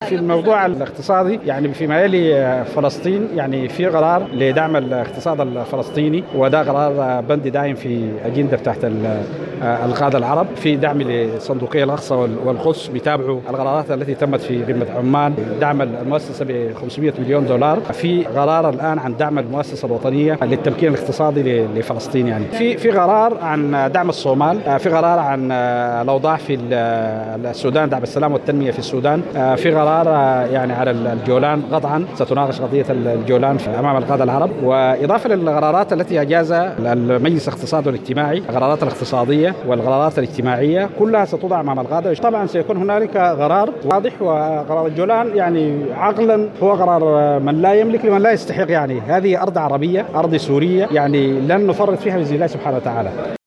وأبرز في تصريح لمراسلة الجوهرة اف ام، أنه تقرر دعم الاقتصاد الفلسطيني ودعم المؤسسة الفلسطينية للتمكين الاقتصادي بقيمة 500 مليون دينار، إلى جانب دعم الصومال والسودان.